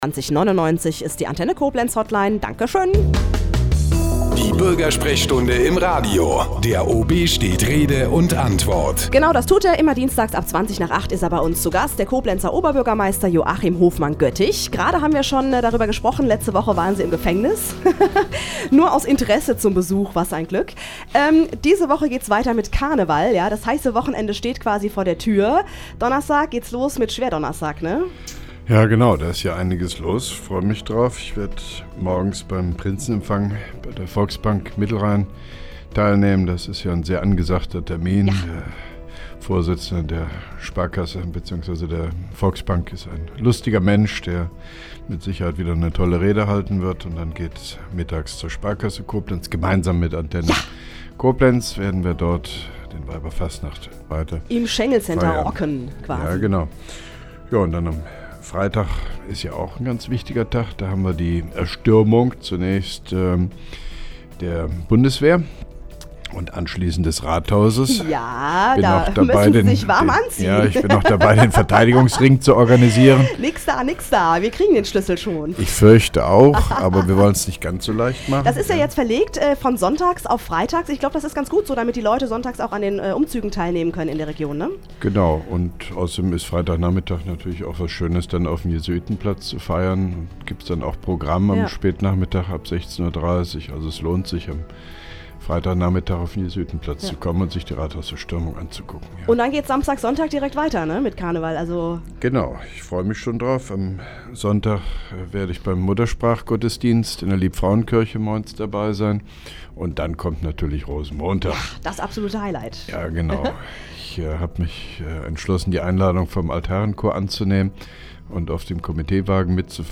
(2) Koblenzer Radio-Bürgersprechstunde mit OB Hofmann-Göttig 01.03.2011